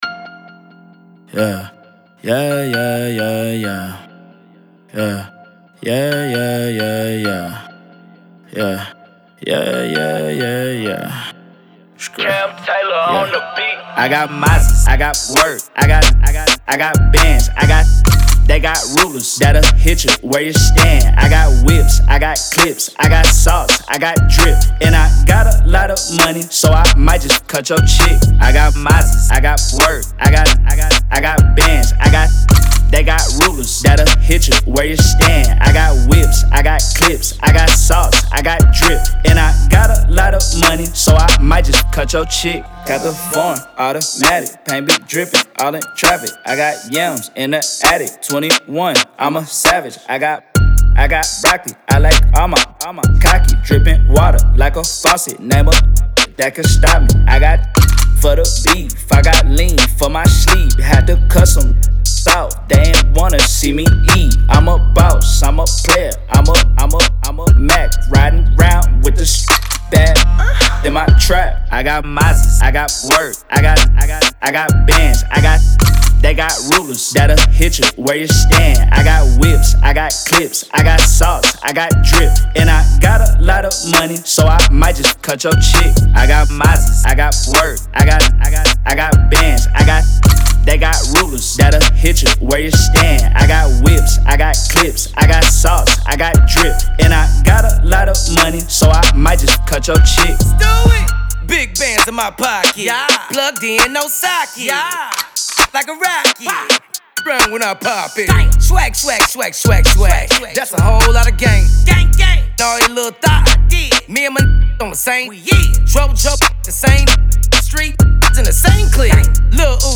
SINGLESHIP-HOP/RAP